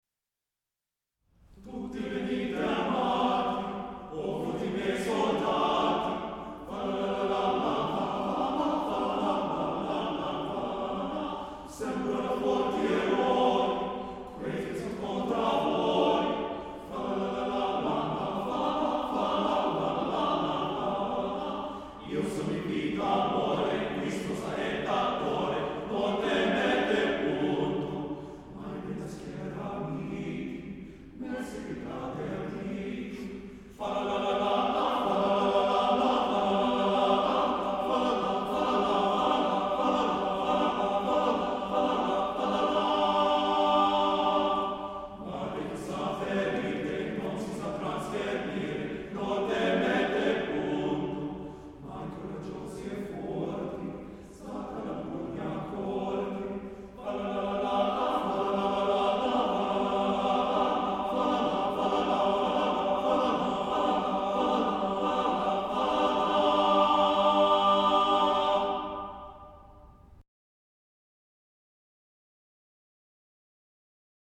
Voicing: TTB/TBB/TTBB